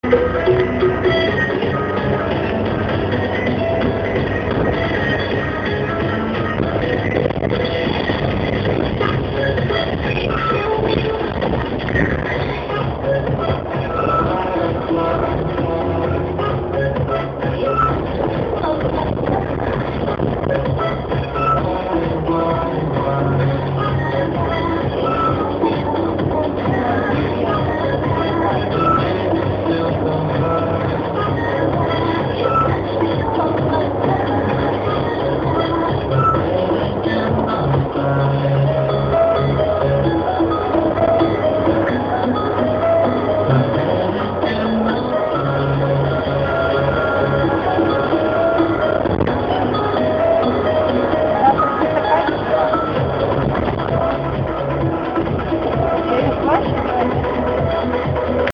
Для этого Вам нужно написать дату и время, желательно прикрепить файл, незабывайте о том что у Вас в телефоне есть диктофон, записывайте фрагмент из песни и прикрепляйте его к сообщению(доступно только зарегистрированным пользователям)